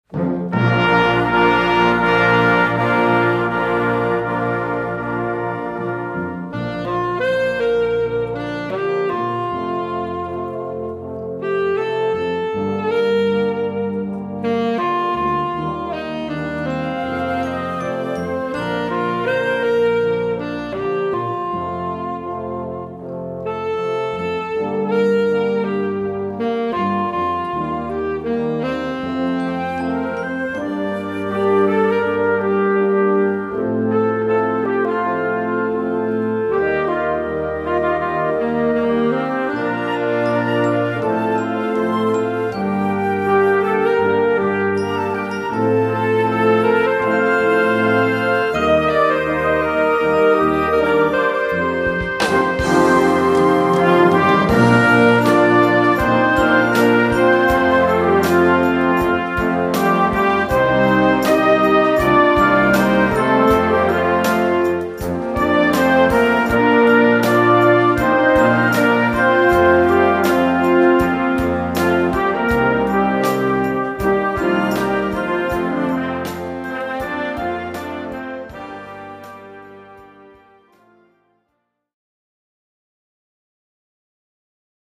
Concert Band ou Harmonie